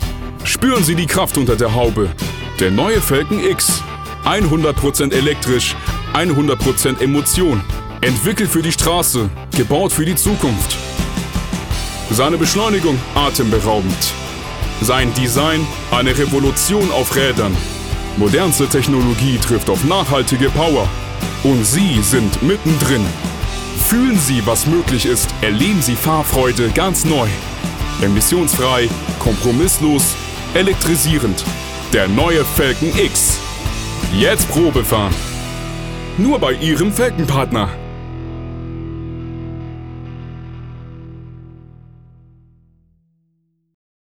Tiefe Stimme
Sprechprobe: Werbung (Muttersprache):